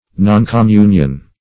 Noncommunion \Non`com*mun"ion\, n.